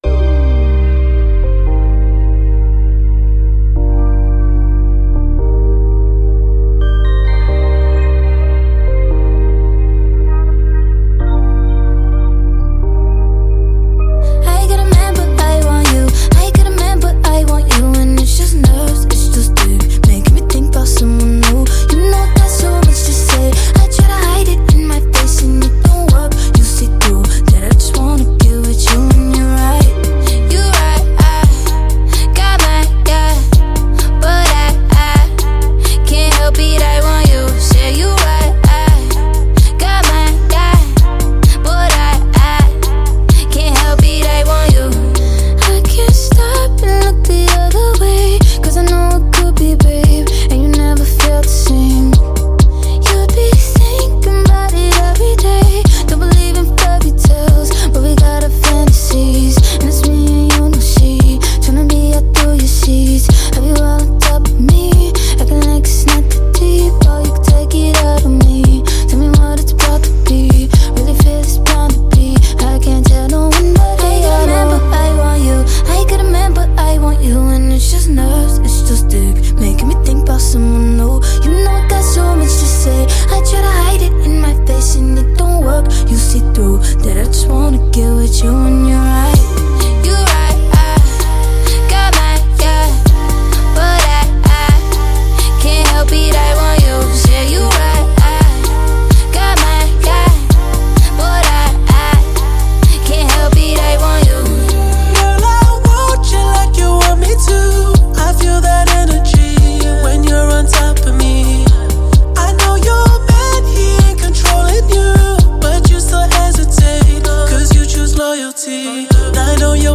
سبک اثر : پاپ , R&B و هیپ هاپ